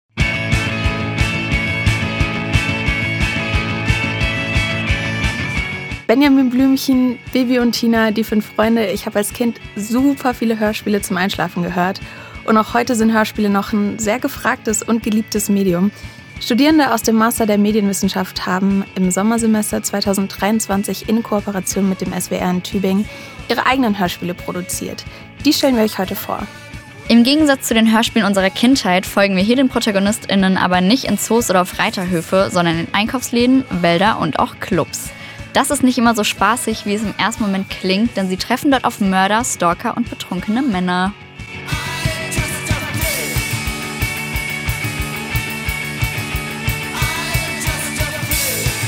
Im Sommersemester 2023 haben Masterstudierende der Medienwissenschaft an der Uni Tübingen in Gruppenarbeit Kurzhörspiele entworfen und produziert.
Studierende aus dem Masterstudiengang Medienwissenschaft produzierten im Sommersemester 2023 in Kooperation mit dem SWR Studio Tübingen ihre eigenen Hörspiele.